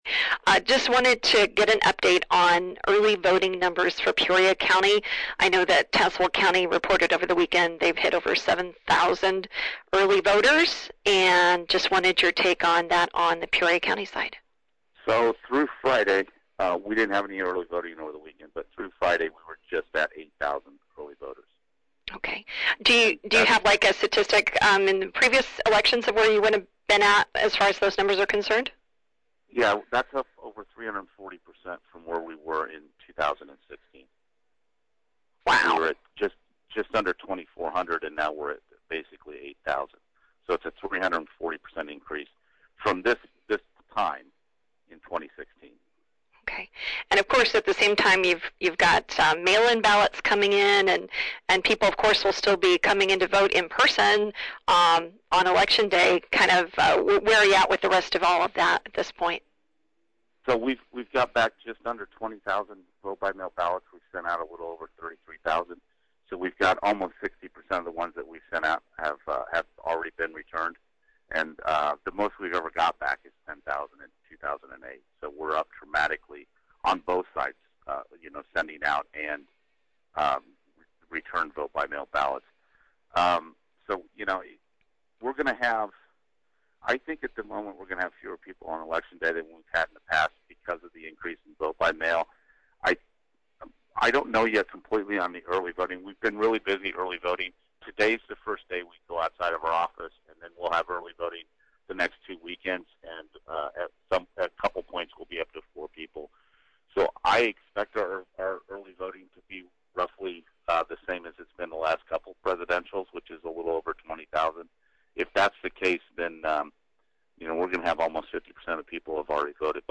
Here’s full interview